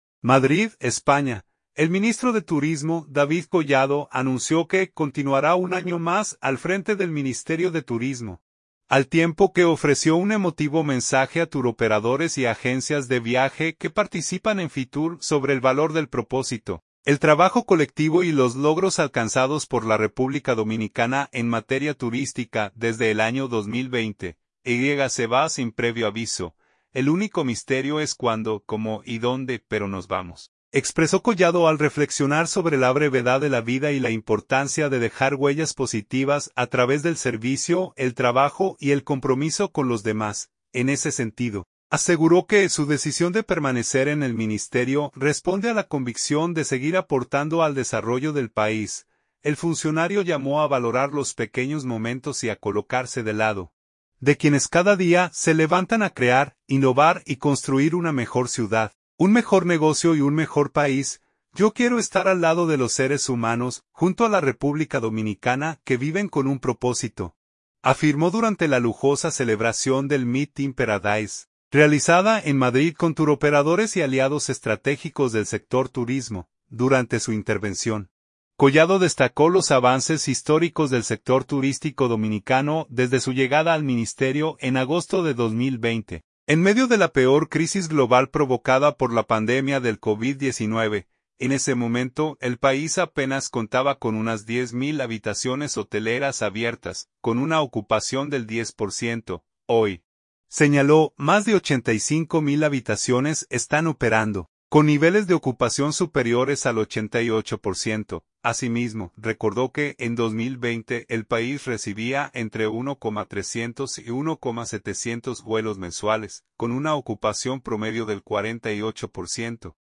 “Yo quiero estar al lado de los seres humanos, junto a la República Dominicana, que viven con un propósito”, afirmó durante la lujosa celebración del Meet in Paradise, realizada en Madrid con turoperadores y aliados estratégicos del sector turismo.